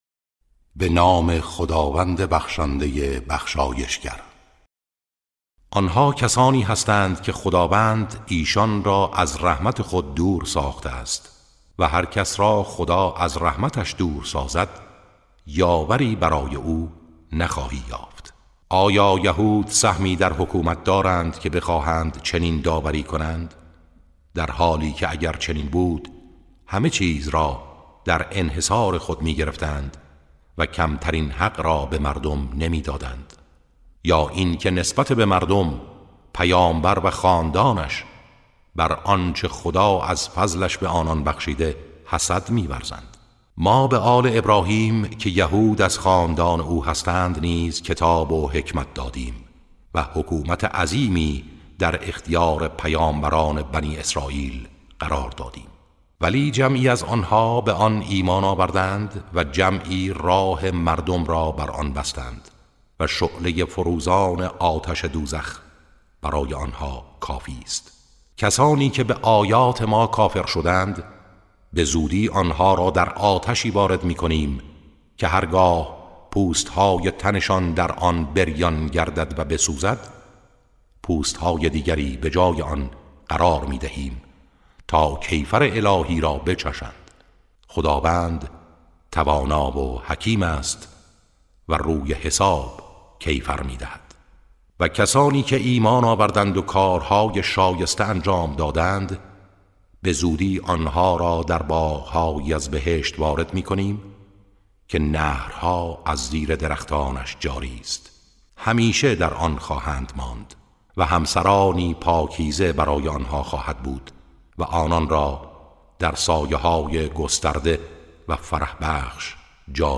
ترتیل صفحه 87 از سوره نساء(جزء پنجم)
ترتیل سوره (نساء)